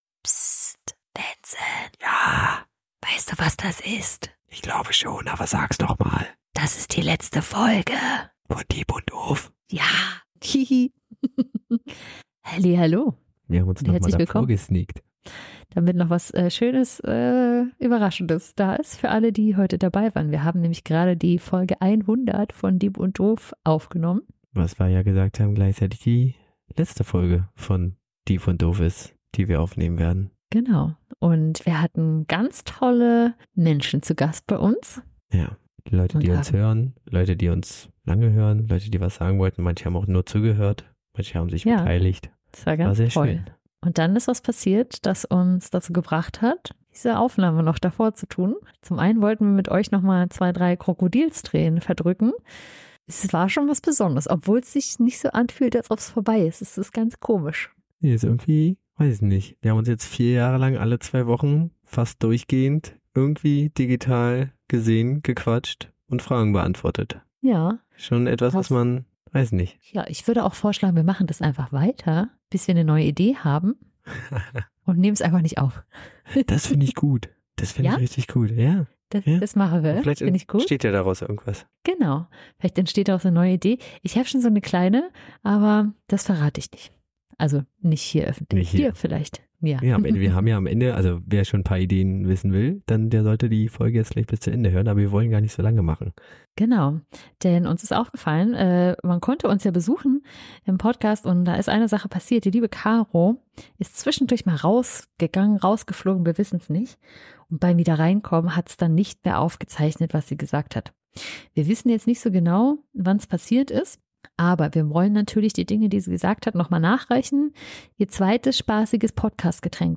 Live und mit Guests